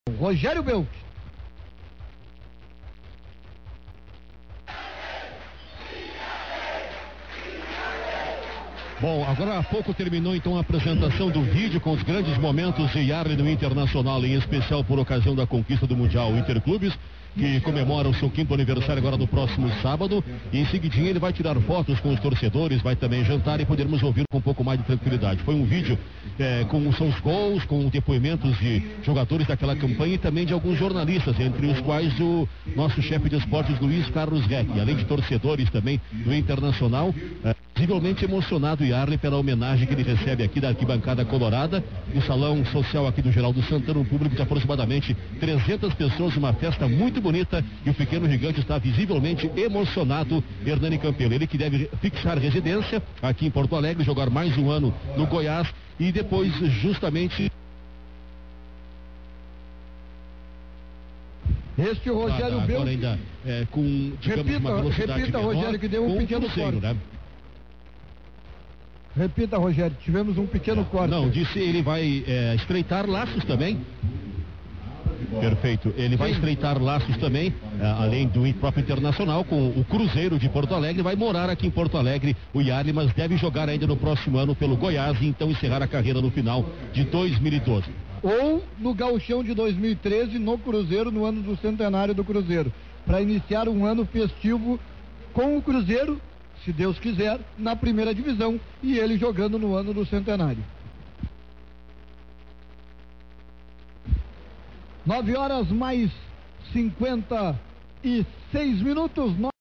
Transmissão Rádio Guaíba Homenagem Iarley – parte 04